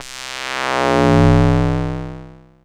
08 Vzzt C.wav